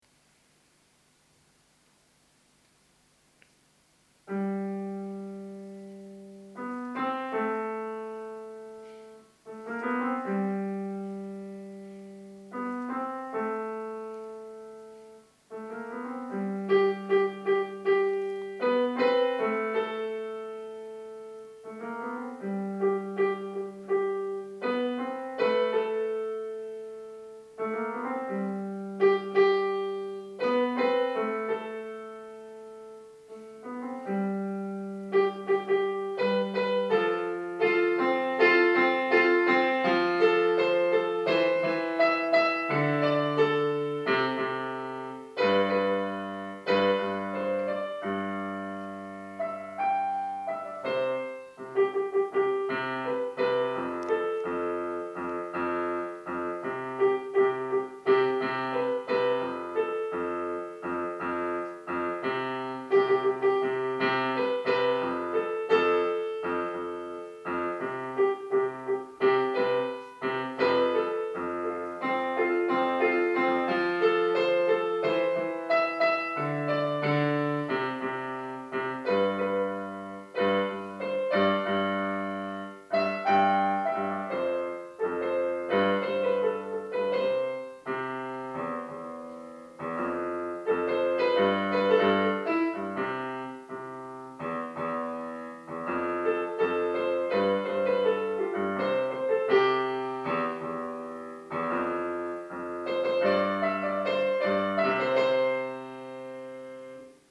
- Tonalidad: Do Mayor
Interpretaciones en directo.
Entera Piano
Entera-piano.MP3